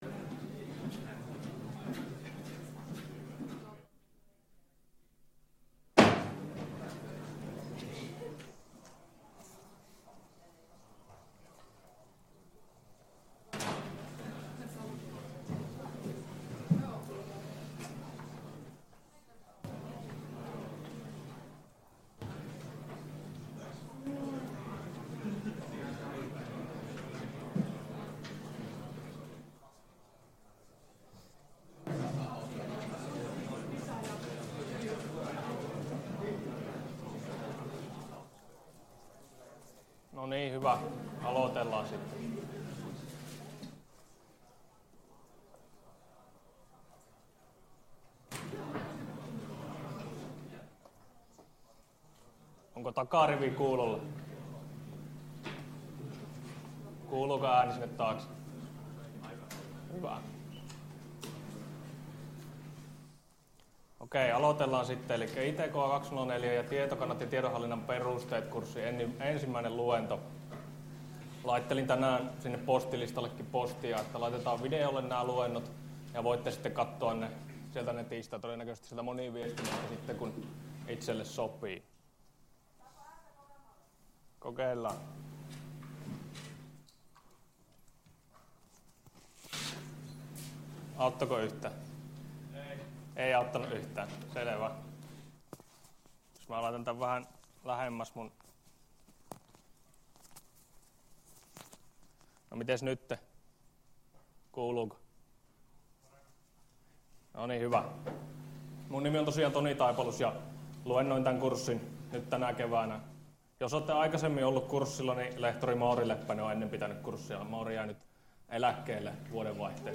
Luento 1 — Moniviestin